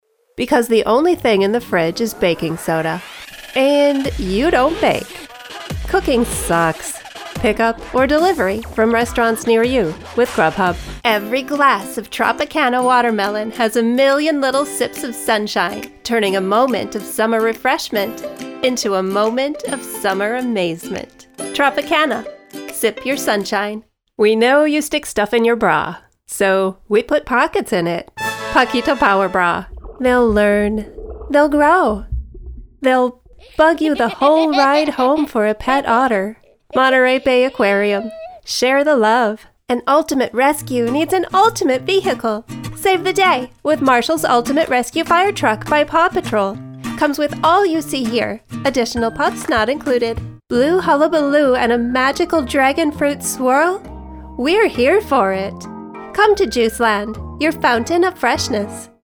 Commercial Demo
English (North American)
Commercial demo_mixdown.mp3